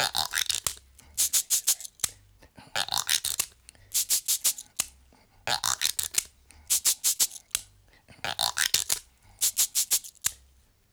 88-PERC9.wav